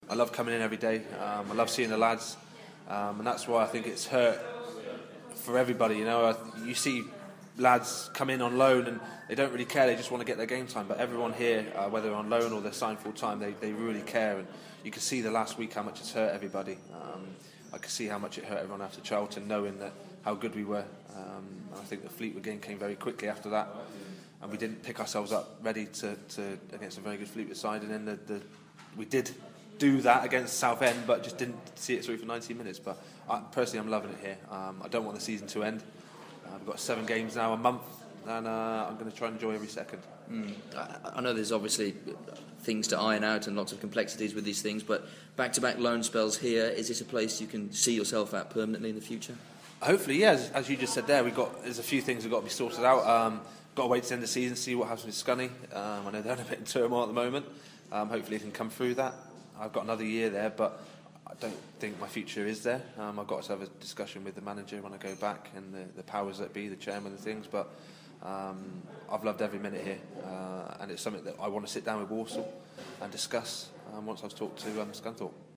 Walsall's on-loan defender Scott Laird tells BBC WM he's loved every minute with The Saddlers - and hopes to discuss a permanent deal in the summer.